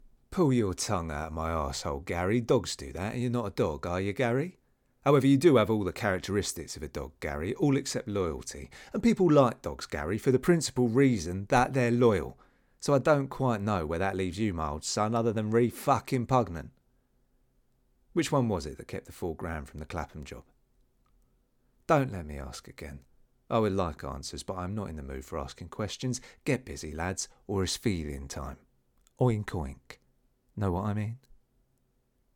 Cockney accent
Cockney-Accent-Sample.mp3